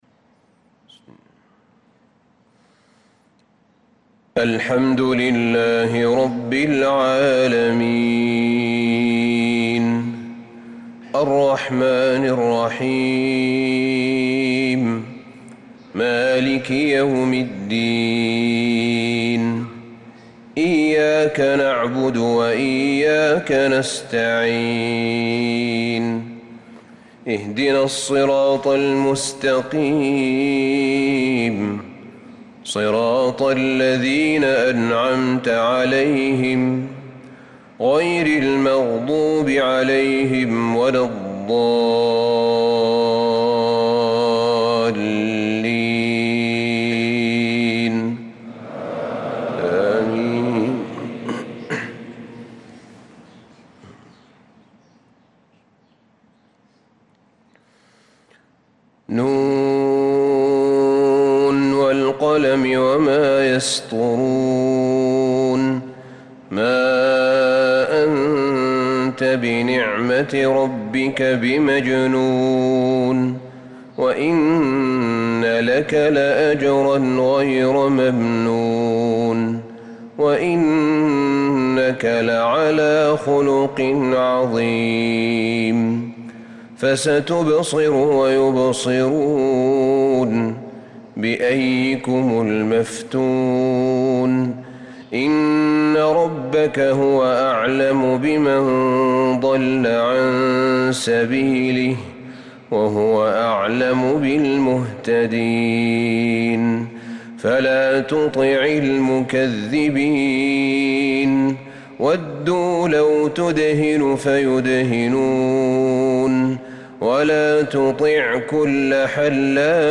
صلاة الفجر للقارئ أحمد بن طالب حميد 16 محرم 1446 هـ